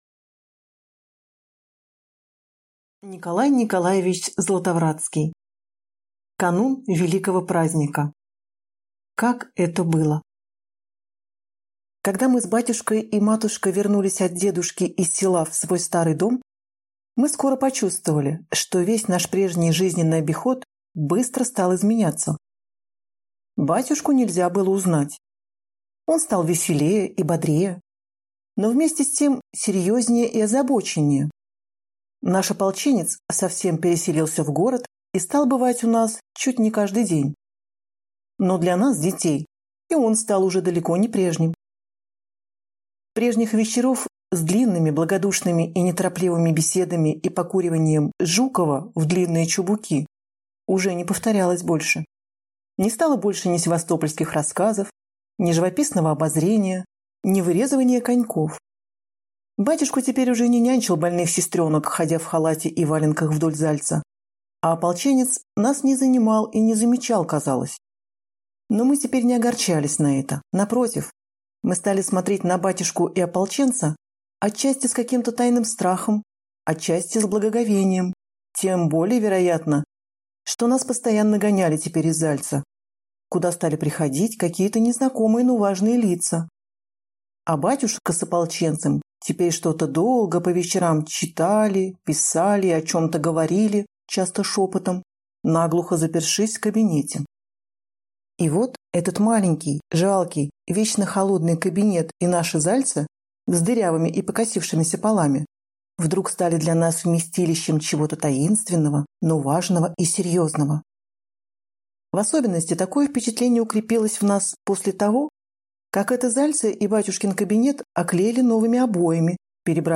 Аудиокнига Канун «великого праздника» | Библиотека аудиокниг